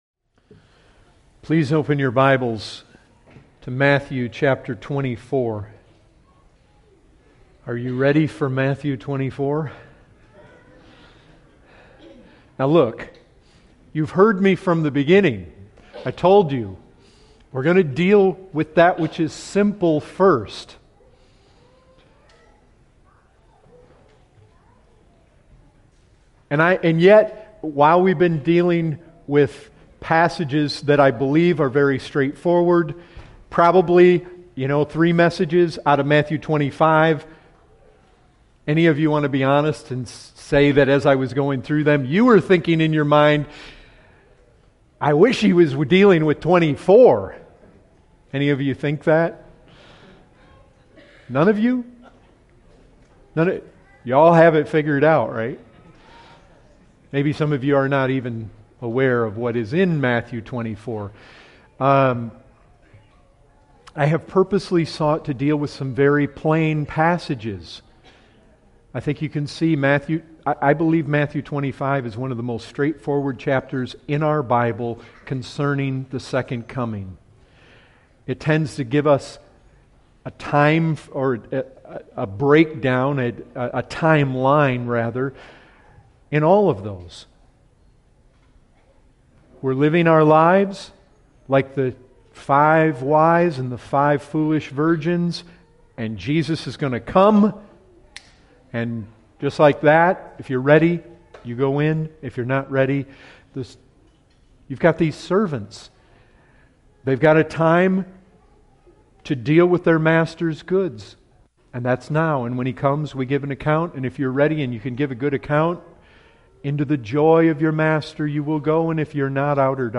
Category: Full Sermons